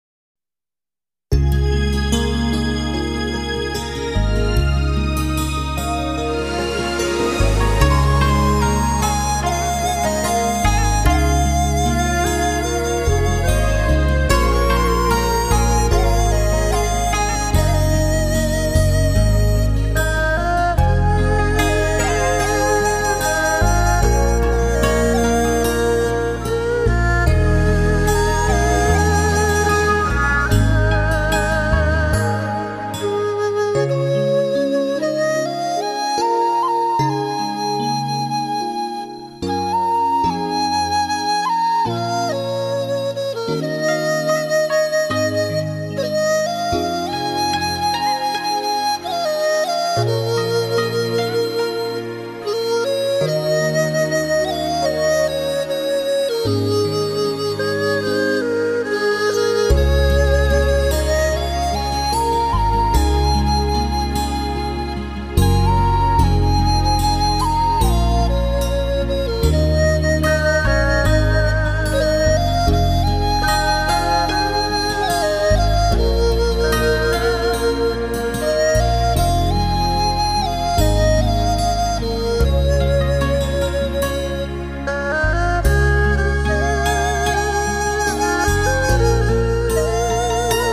东方音乐